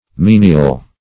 Menial \Men"ial\, n.